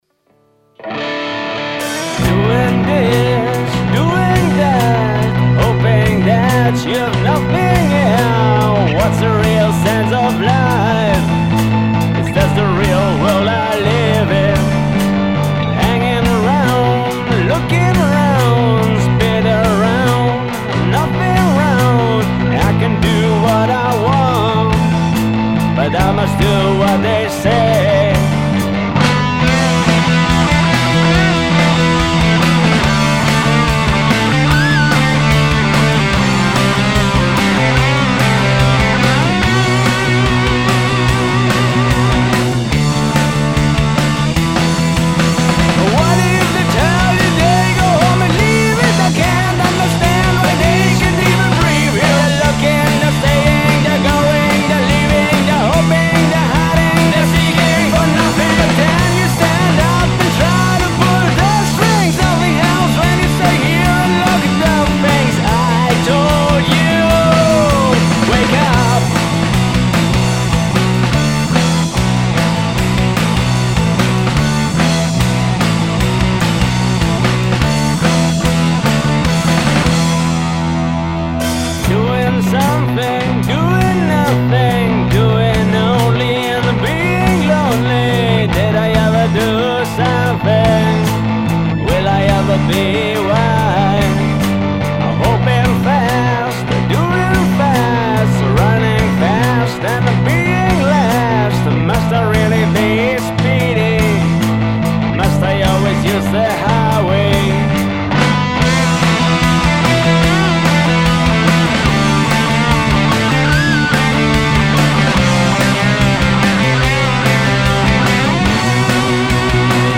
Blues und Rock aus den 70er, 80er und 90er.
vocals/guitar
bass
drums